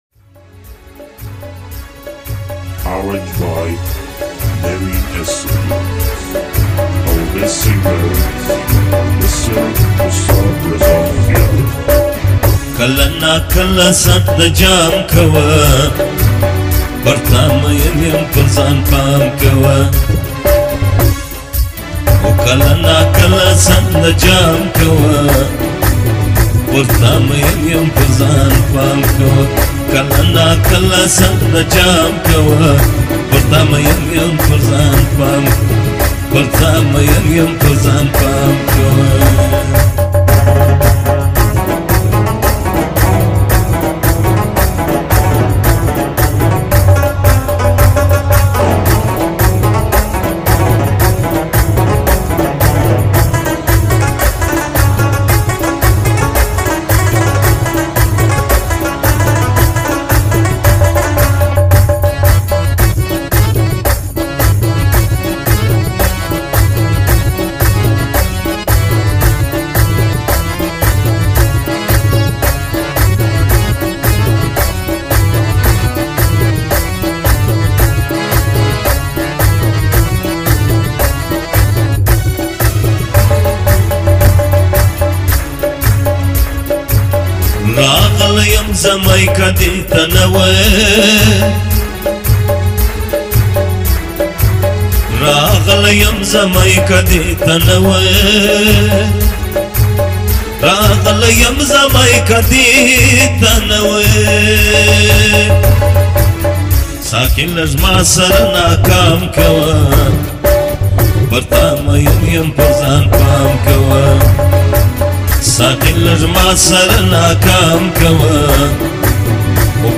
Pashto Song